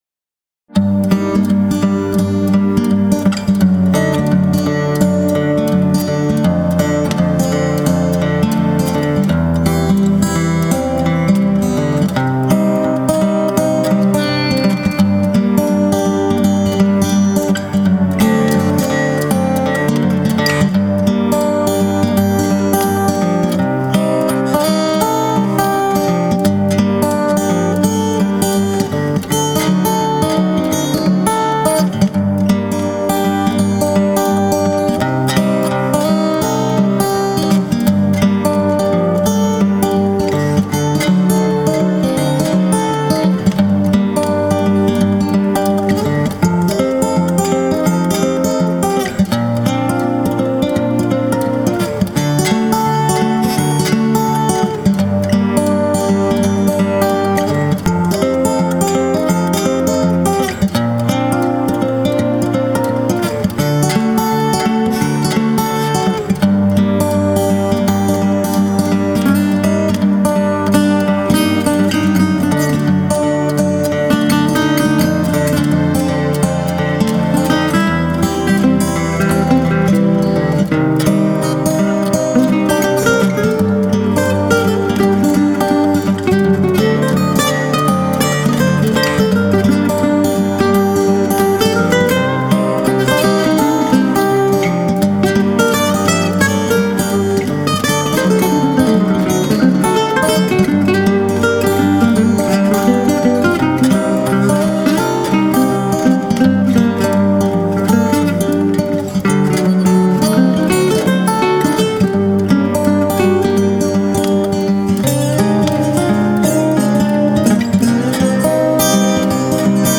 guitares classiques, acoustiques, électriques et Dobro
Claviers et Programmations
Caisse claire, Djembé, Cajon et percussions diverses
Guitare flamenca
Guitare manouche
Violon
Guitare électrique
Doudouk